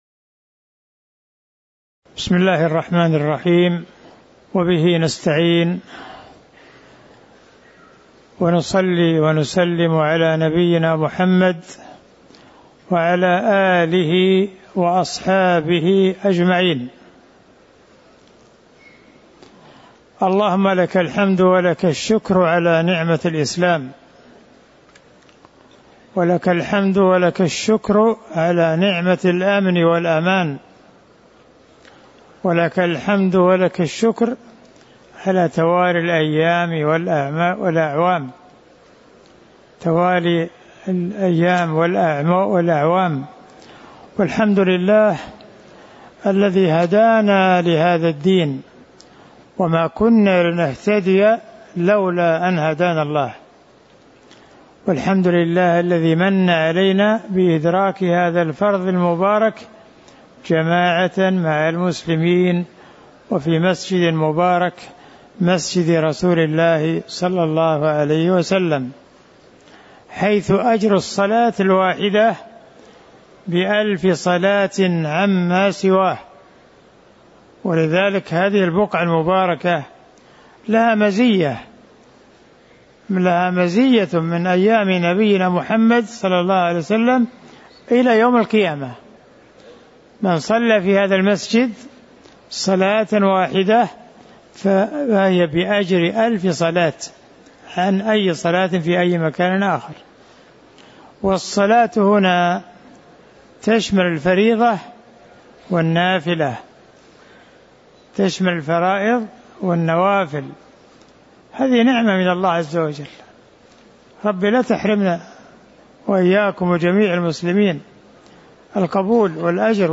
تاريخ النشر ٢٤ ذو القعدة ١٤٤٥ هـ المكان: المسجد النبوي الشيخ